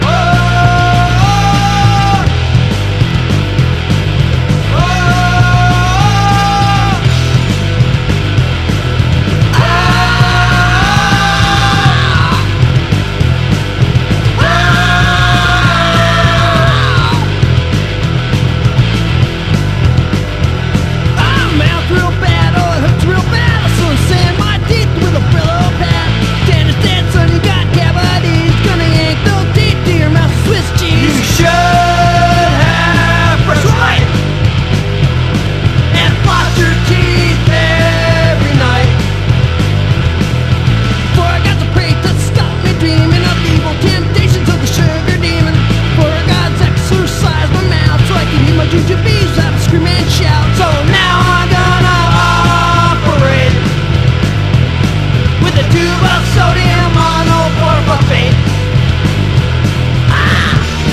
90'Sポゴ・ダンシングな名曲・人気曲の連射！